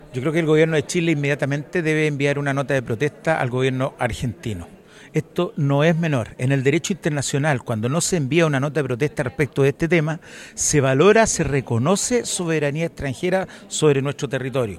Por su parte, el diputado del Partido Socialista e integrante de la Comisión de Relaciones Exteriores, Nelson Venegas, hizo un llamado a que el Gobierno de Chile eleve una carta de protesta.